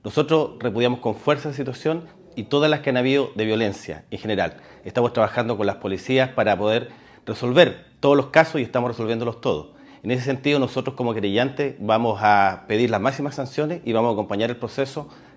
Como querellantes, el delegado presidencial, Jorge Alvial, afirmó que están buscando resolver este y otros casos similares en Valdivia.